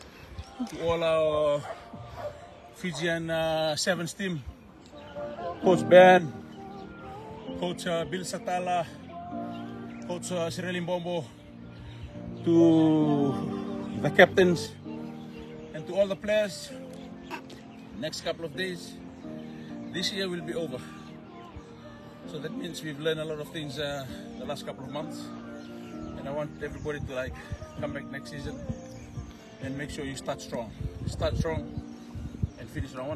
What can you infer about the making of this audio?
In a live video on his Facebook page, he highlights the significance of the upcoming year for the 7s side.